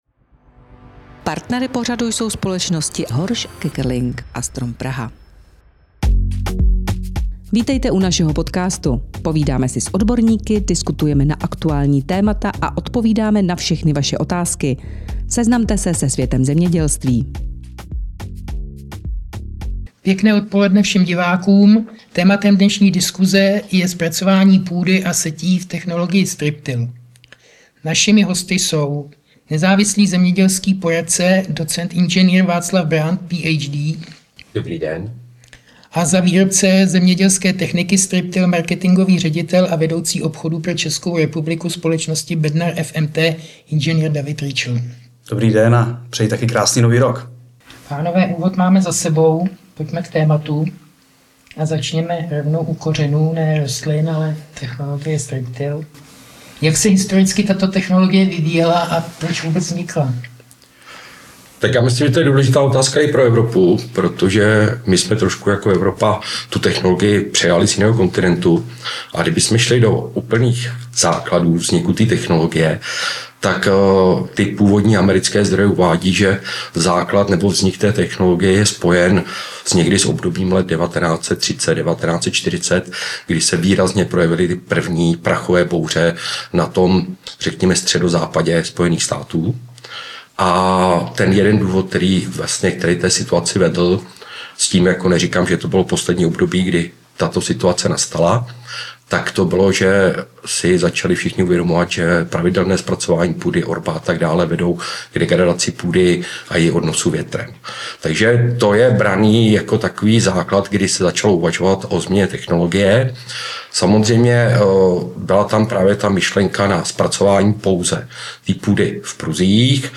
Tisková konference